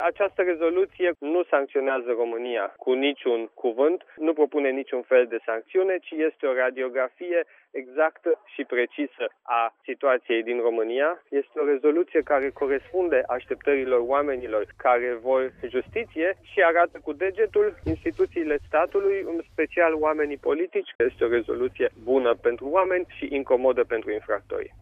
La rândul său, europarlamentarul PNL, Siegfried Mureşan, consideră că rezoluţia corespunde asteptărilor românilor, care au cerut o bună guvernare, stat de drept şi valori europene: